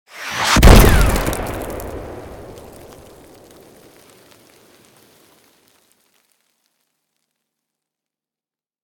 low mid afar explosion 1